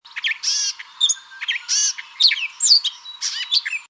Dumetella carolinensis (gray catbird)
Gray Catbird, a small section of a long, rambling song, Cincinnati, Ohio